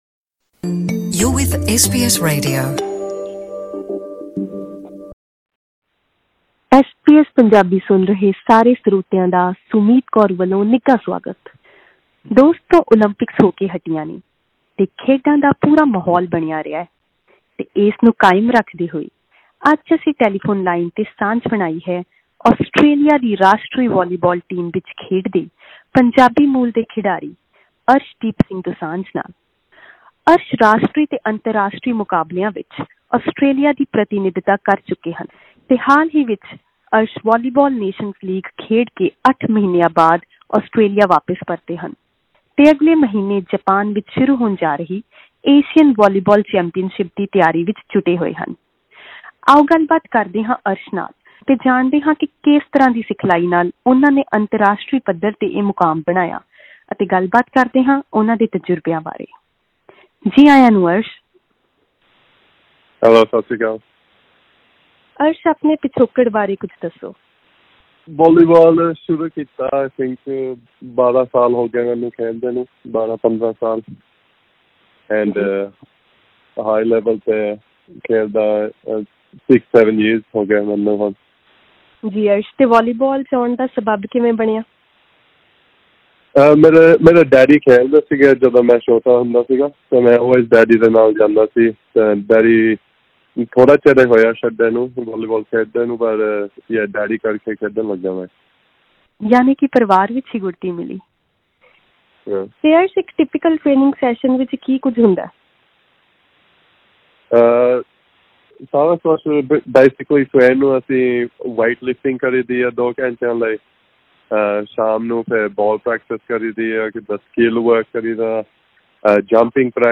The setter who has represented the country in many major national and international championships opened up about his journey in the court in an interview with SBS Punjabi.